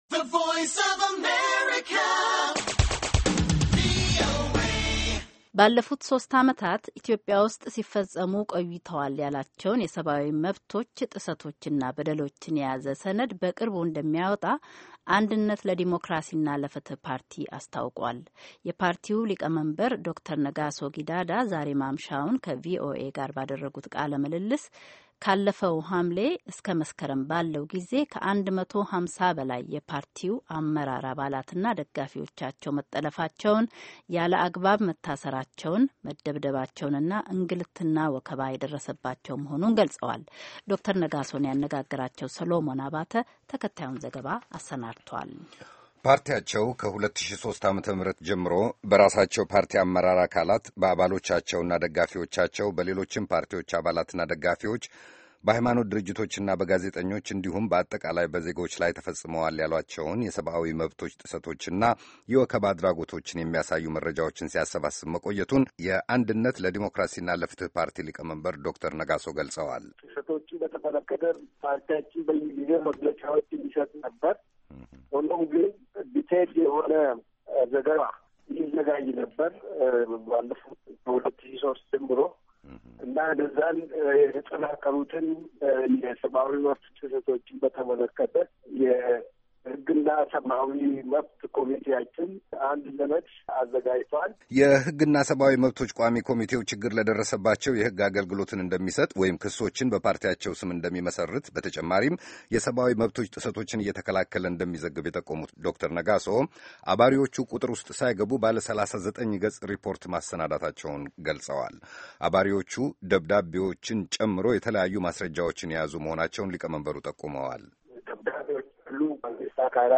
የፓርቲው ሊቀመንበር ዶ/ር ነጋሦ ጊዳዳ ዛሬ ማምሻውን ከቪኦኤ ጋር ባደረጉት ቃለ-ምልልስ ካለፈው ሐምሌ እስከ መስከረም ባለው ጊዜ ከ150 በላይ የፓርቲያቸው አመራር አባላትና ደጋፊዎቻቸው መጠለፋቸውን፤ ያለአግባብ መታሠራቸውን መደብደባቸውንና እንግልትና ወከባ የደረሰባቸው መሆኑን ገልፀዋል፡፡